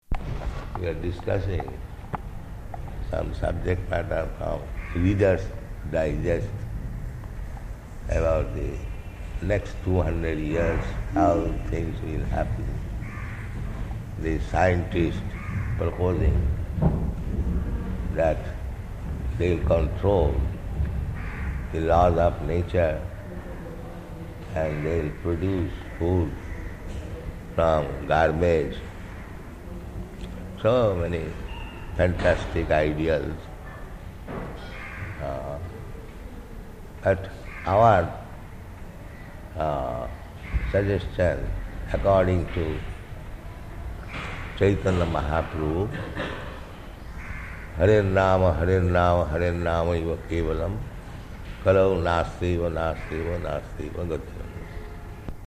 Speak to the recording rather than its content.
Location: Calcutta Room Conversation on 1976 Book ScoresArrival [partially recorded]